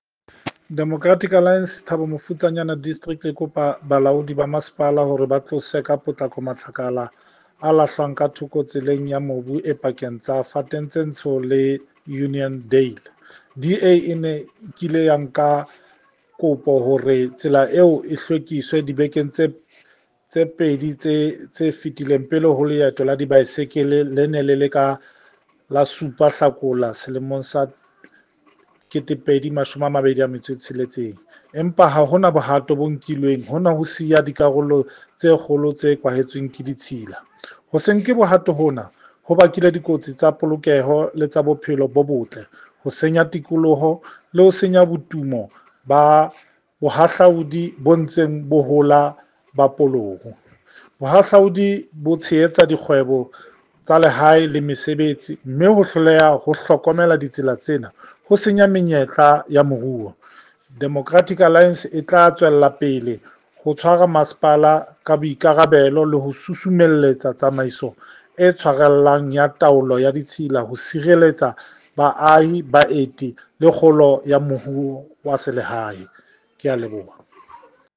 Sesotho soundbite by Cllr Sello Makoena